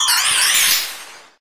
fixes missing minior cry